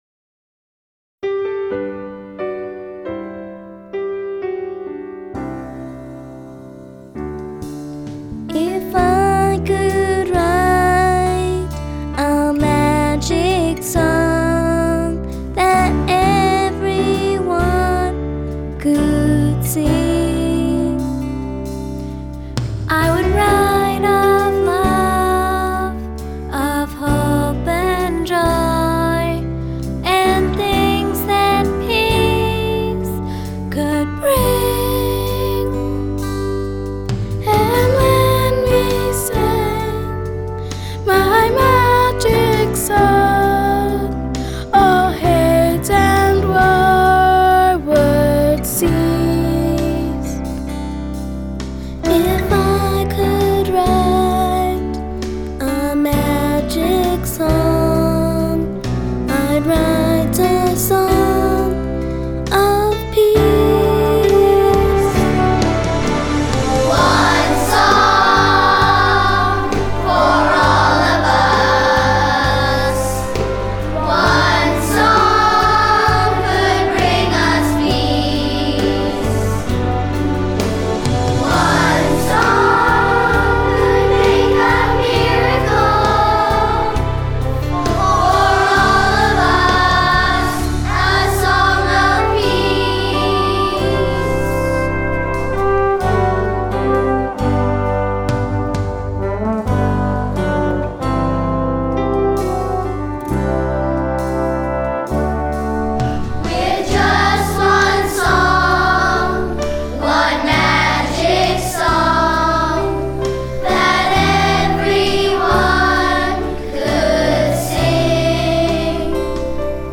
The worst recordings I have ever heard have come from elementary schools.
The ingredients for napalm are one batch of children under the age of 10 singing, and one batch of children under the age of 10 playing the recorder.